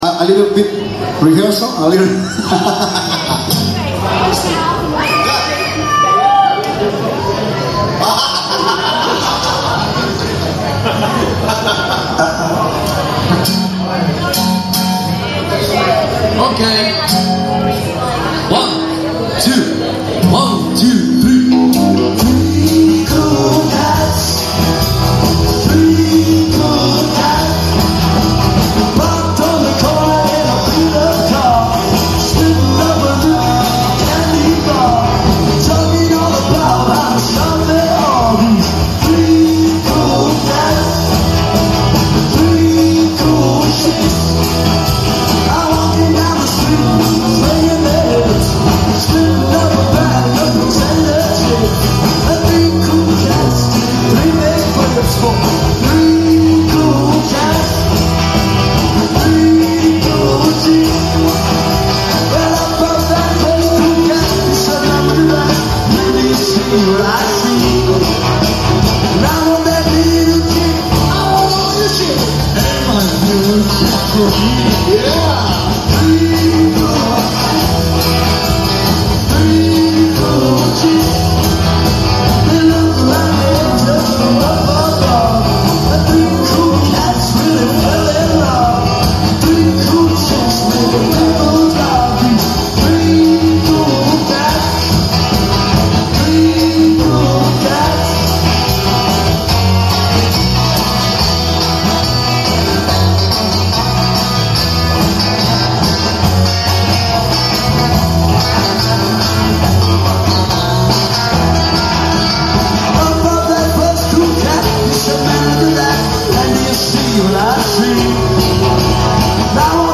LIVE COVER IN THE WORLD FAMOUS CAVERN CLUB LIVERPOOL